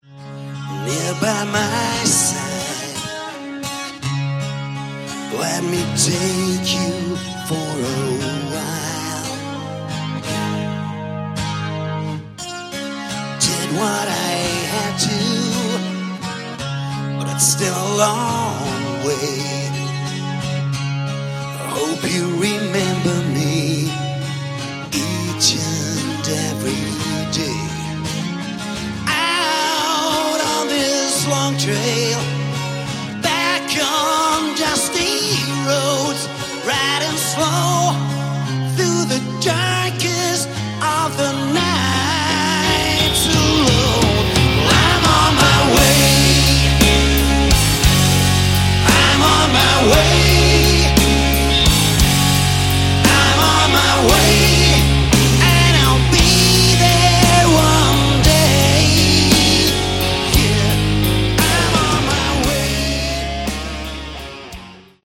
Category: Melodic Hard Rock
vocals, guitar
bass
drums